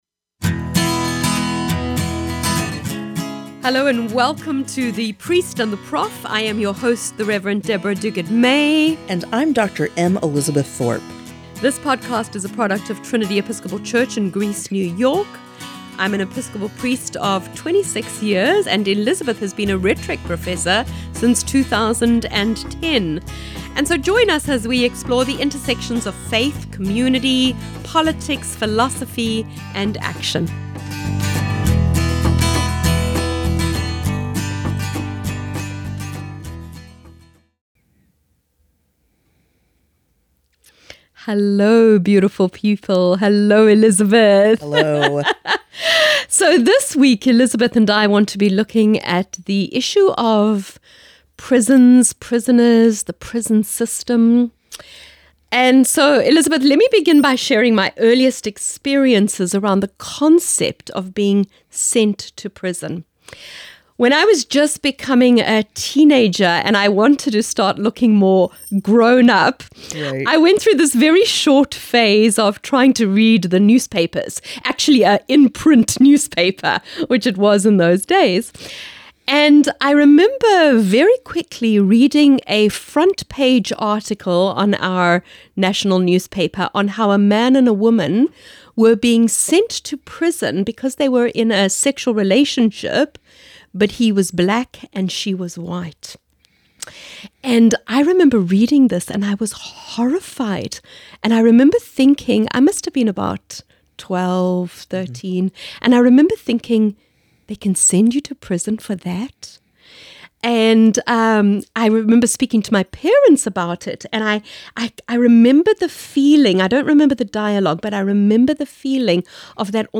A new MP3 sermon from Westminster Presbyterian Church is now available on SermonAudio with the following details:
Event: Sunday - PM